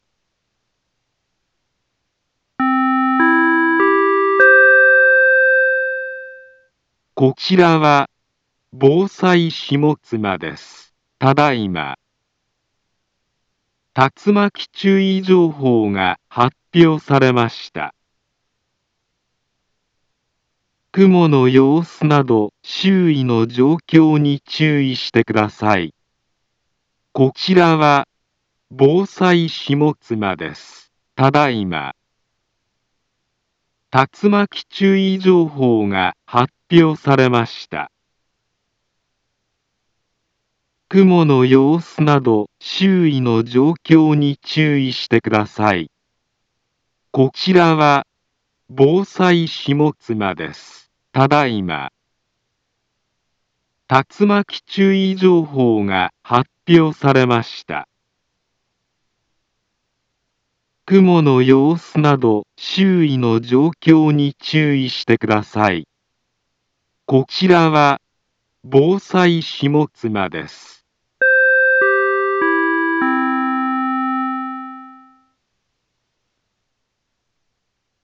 Back Home Ｊアラート情報 音声放送 再生 災害情報 カテゴリ：J-ALERT 登録日時：2024-07-27 19:19:29 インフォメーション：茨城県南部は、竜巻などの激しい突風が発生しやすい気象状況になっています。